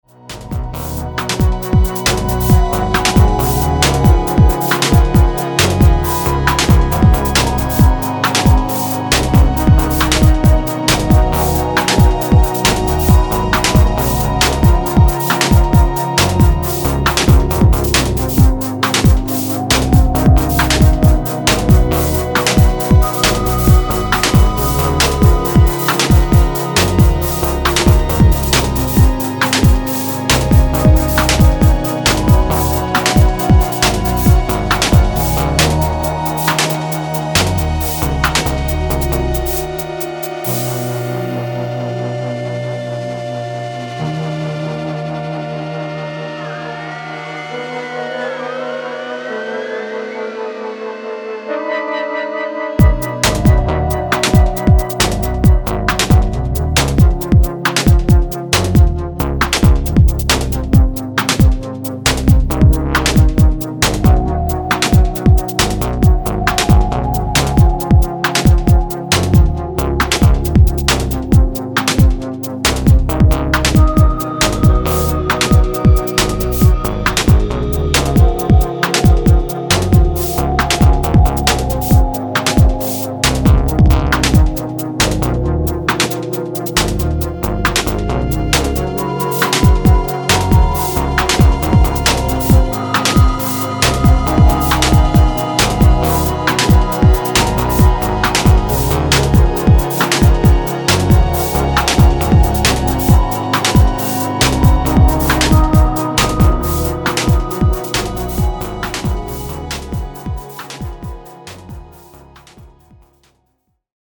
fuses Electro and melancholic sonorities